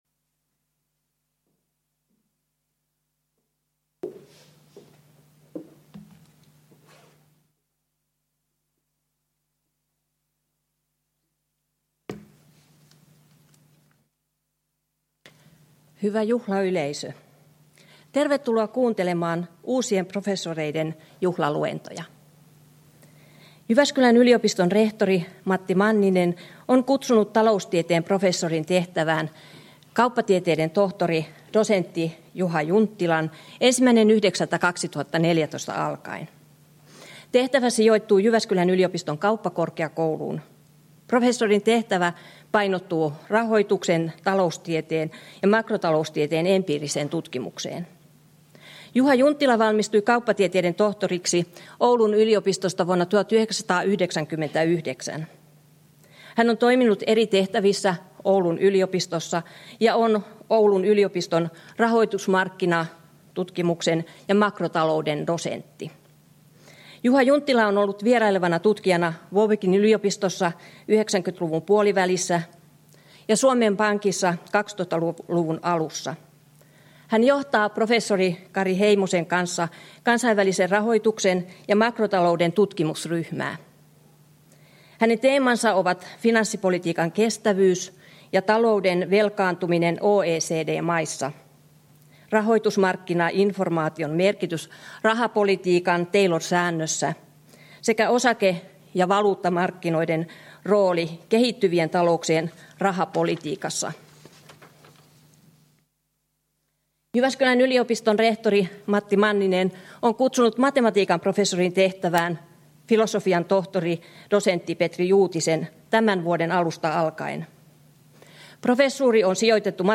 Tilaisuuden avaus, Martti Ahtisaari -sali